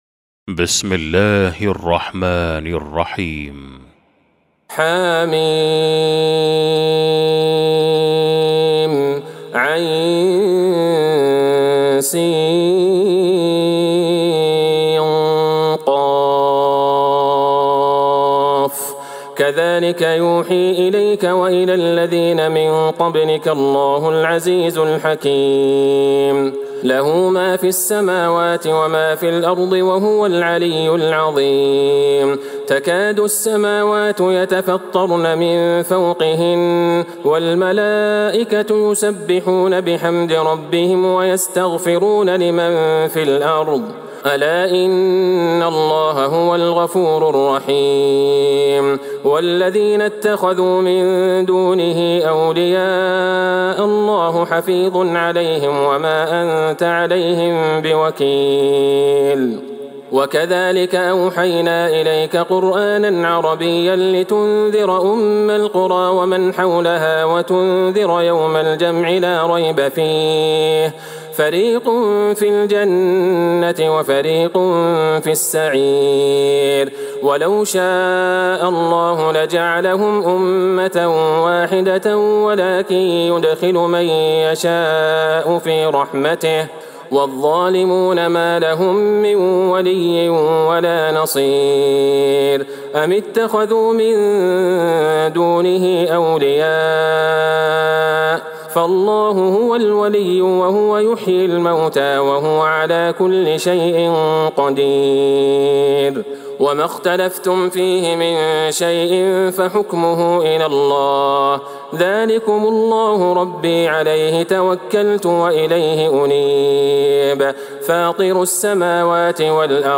سورة الشورى Surat Ash-Shuraa > مصحف تراويح الحرم النبوي عام ١٤٤٣ > المصحف - تلاوات الحرمين